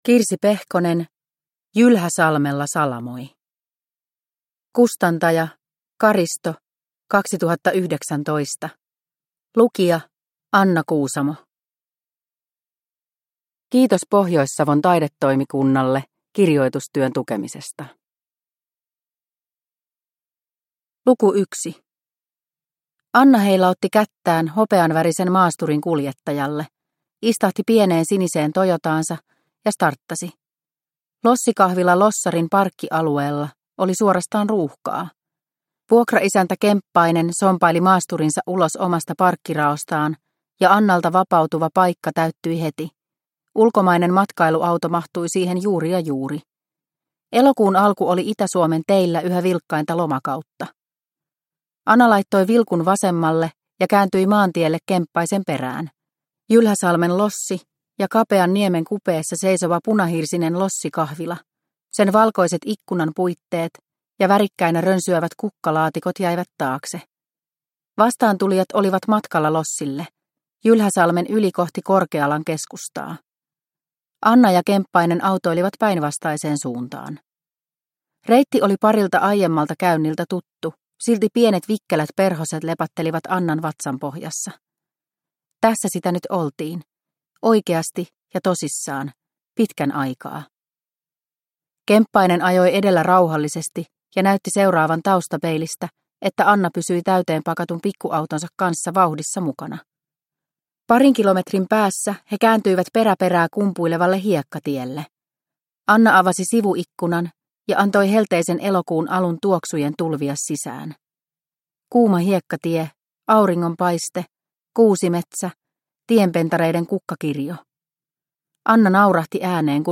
Jylhäsalmella salamoi – Ljudbok – Laddas ner